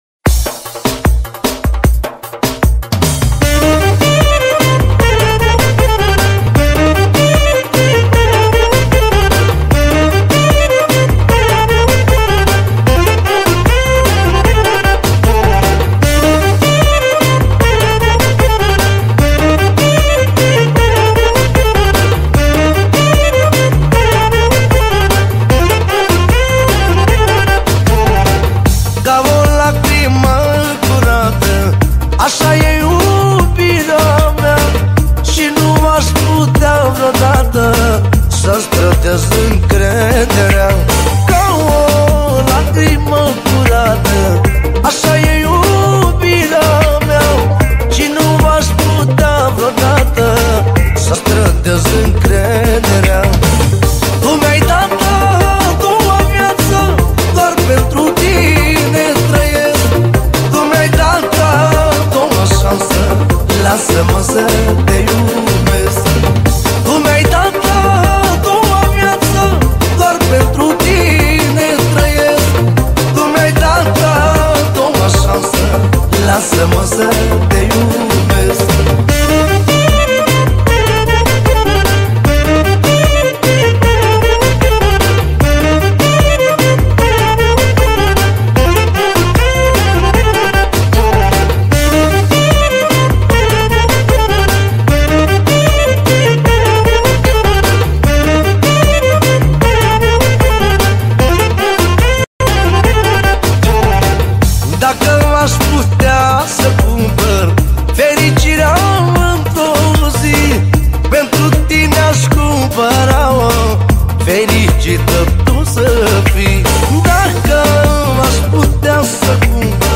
Data: 09.10.2024  Manele New-Live Hits: 0